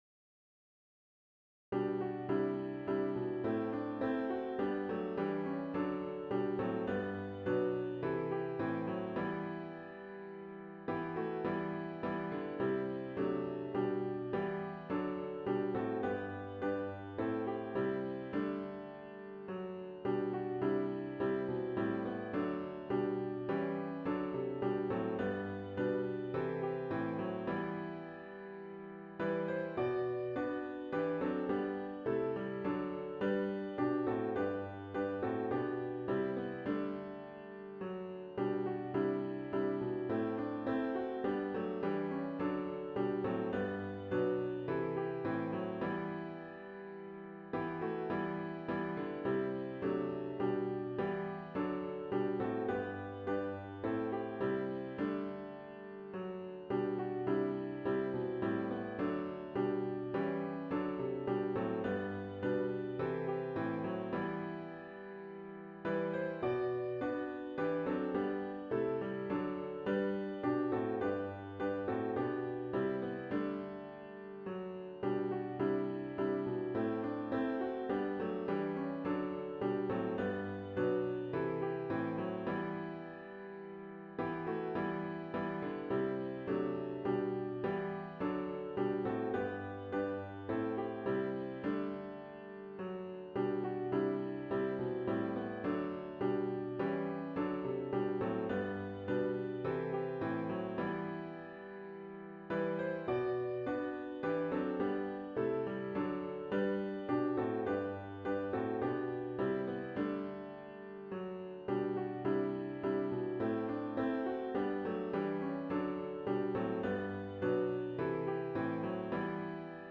Sunday November 23, 2025 worship service
*OPENING HYMN “O Sing a Song of Bethlehem” GtG 159